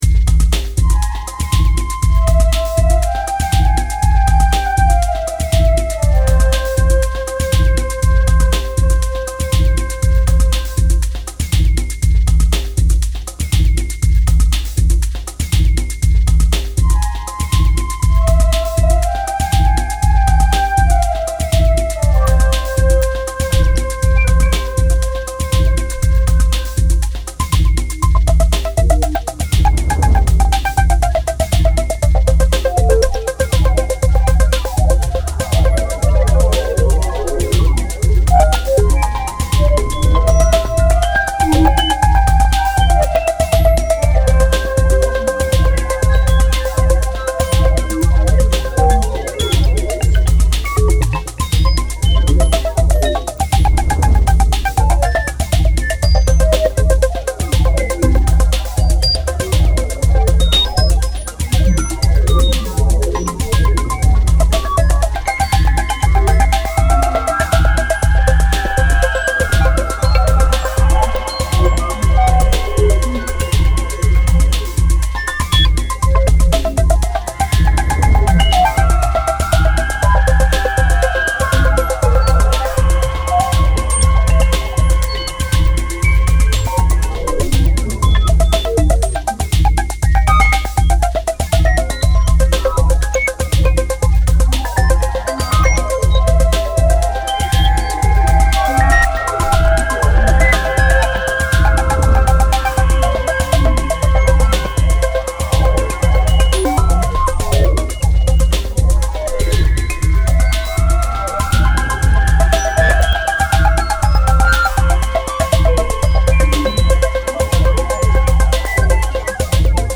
File usage The following page uses this file: Untitled instrumental 090810